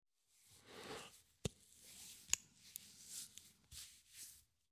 звук потирания бороды мужской рукой